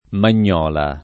Magnola [ man’n’ 0 la ]